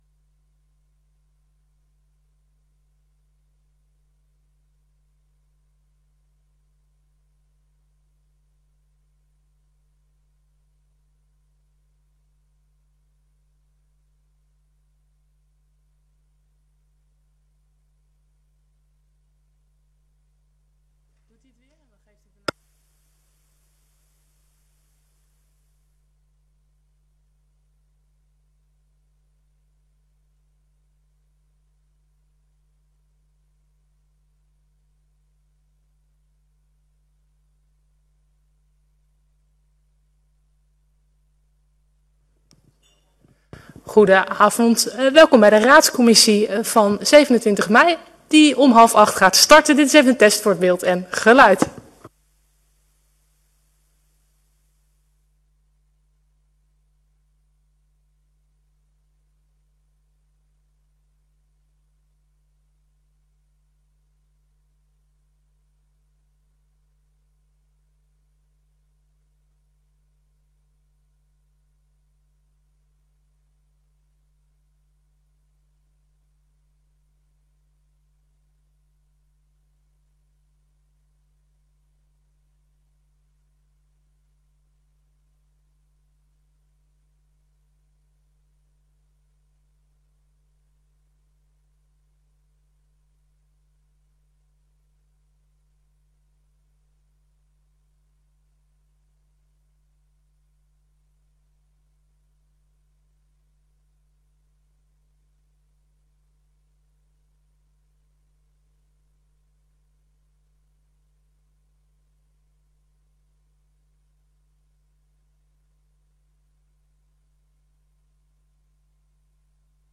Raadscommissie 27 mei 2024 19:30:00, Gemeente Dalfsen
Download de volledige audio van deze vergadering
Locatie: Raadzaal